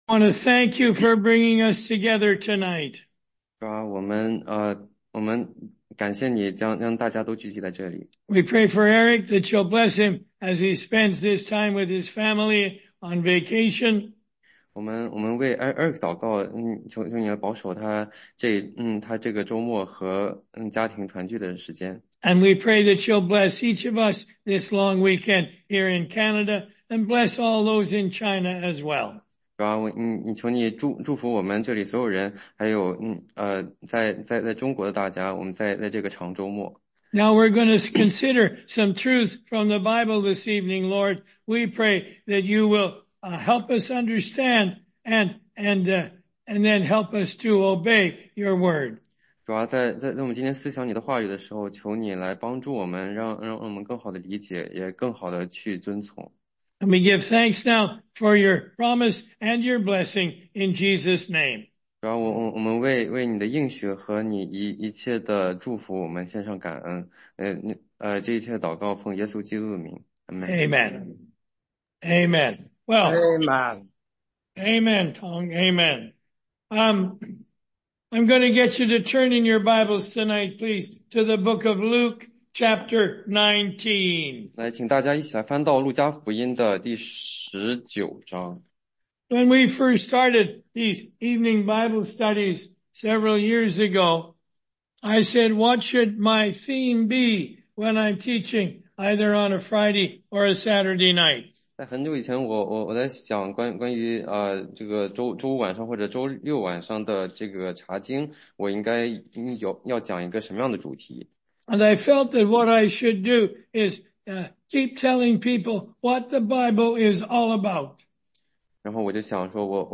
16街讲道录音 - 中英文查经